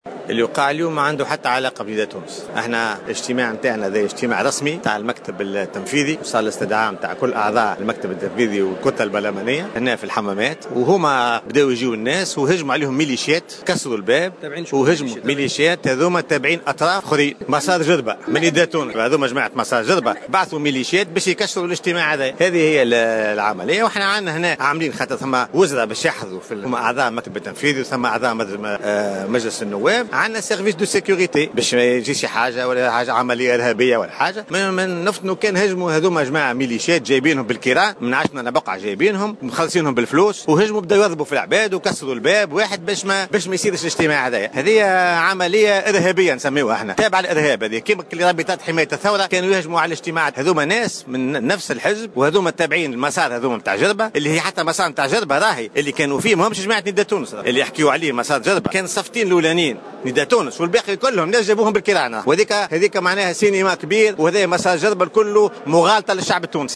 Faouzi Elloumi, leader au sein de Nidaa Tounes a affirmé, lors d'une déclaration accordée à Jawhara FM ce dimanche 1er novembre 2015, que les actes de violence commis ce matin lors de la réunion du bureau exécutif de Nidaa Tounes ont été perpetrés par des milices payées pour semer le chaos.